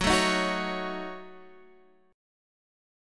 Gbm6 chord